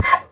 sounds / mob / horse / donkey / hit2.mp3